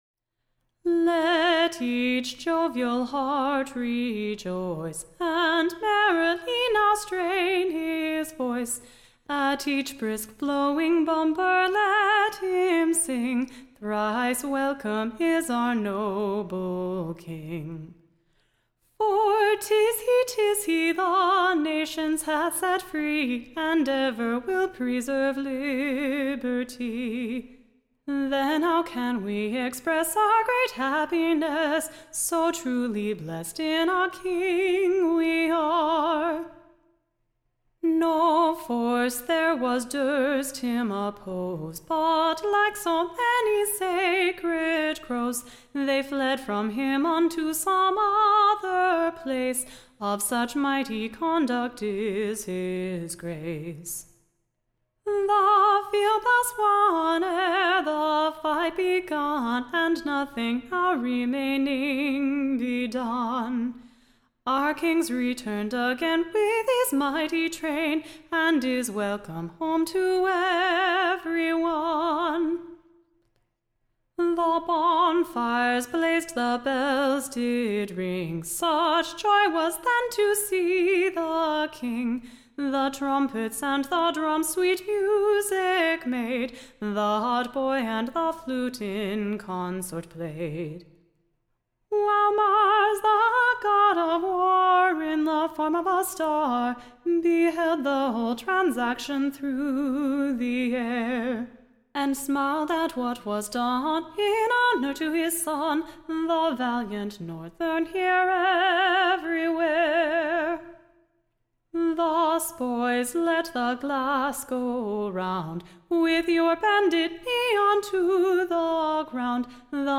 Recording Information Ballad Title The / New Loyal Health, / OR, / King WILLIAM's Welcome / To England.